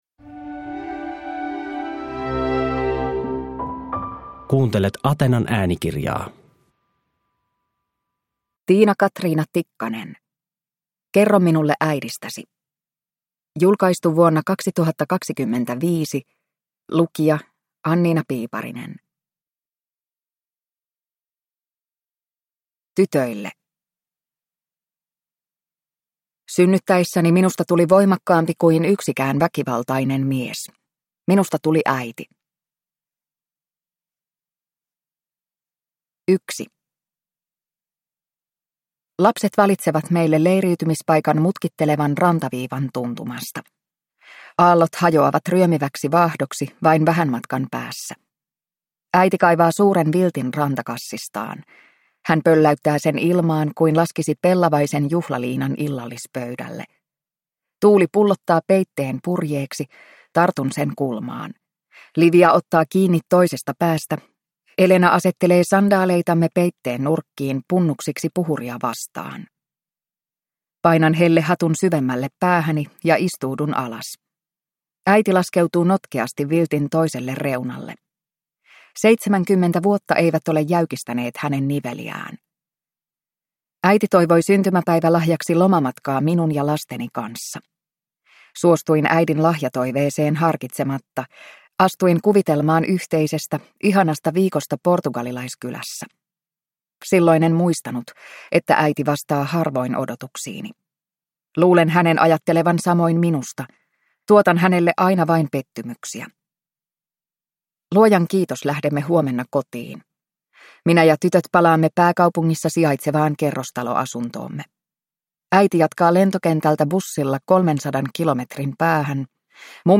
Kerro minulle äidistäsi (ljudbok) av Tiina Katriina Tikkanen